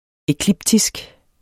Udtale [ eˈklibdisg ]